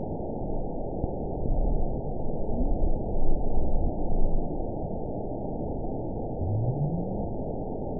event 920550 date 03/30/24 time 00:34:25 GMT (1 year, 8 months ago) score 9.63 location TSS-AB01 detected by nrw target species NRW annotations +NRW Spectrogram: Frequency (kHz) vs. Time (s) audio not available .wav